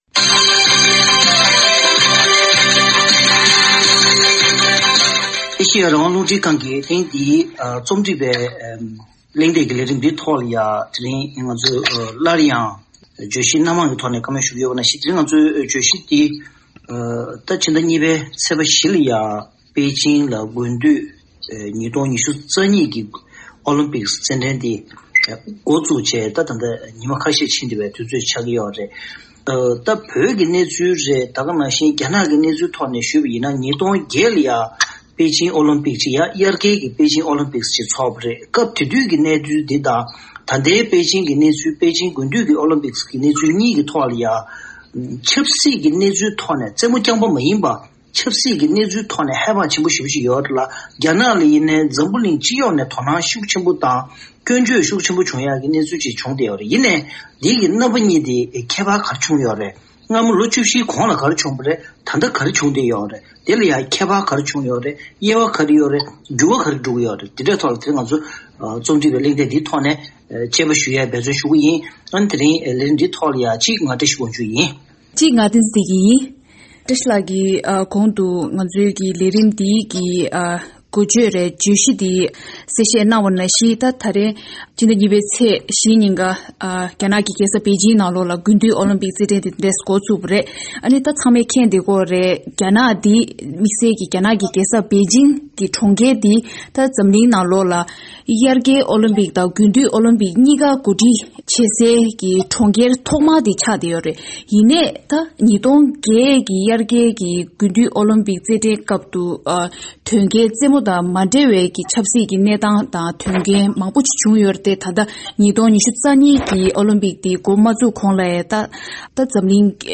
དཔྱད་གླེང་གནང་བའི་ལས་རིམ།